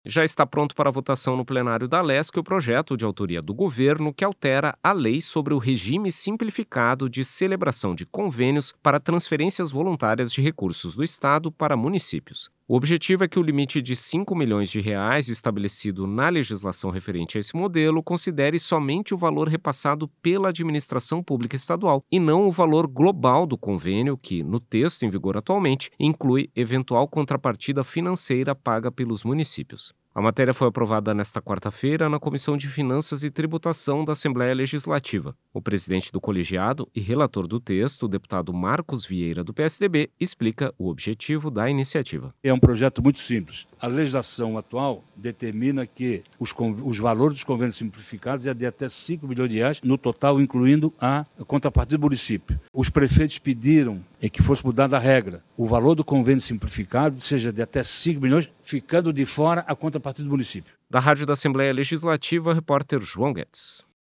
Entrevista com:
- deputado Marcos Vieira (PSDB), relator do Projeto de Lei 56/2025 na Comissão de Finanças e Tributação da Alesc.